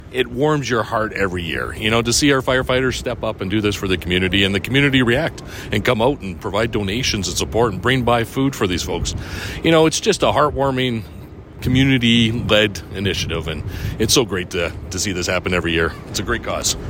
Spruce Grove Mayor Jeff Acker says it warms hearts when people see what the firefighters are doing by bringing the community together to raise money towards a great cause.